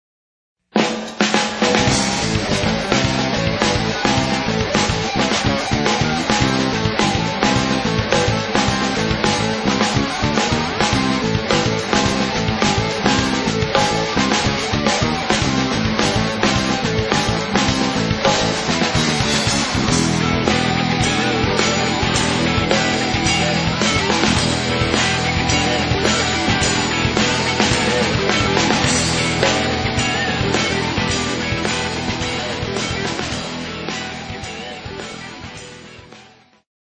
Xtreme / Fusion (Live)